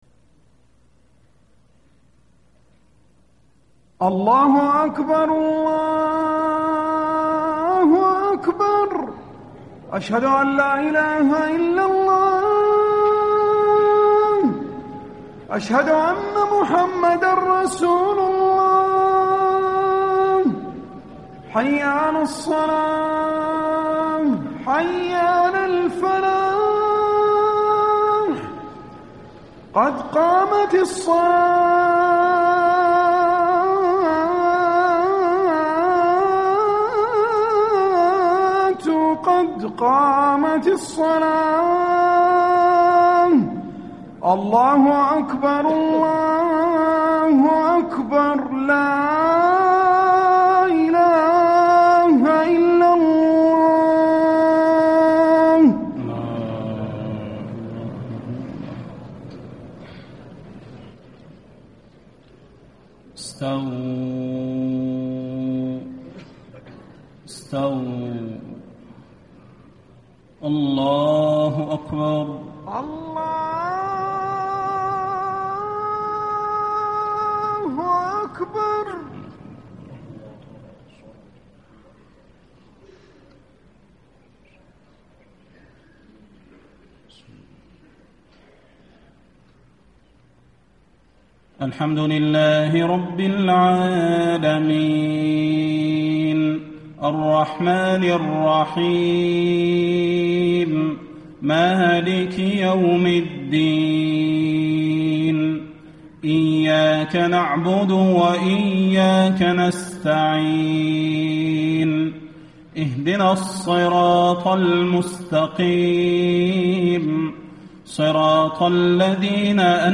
صلاة العشاء 7-6- 1435 ما تيسر من سورة النمل > 1435 🕌 > الفروض - تلاوات الحرمين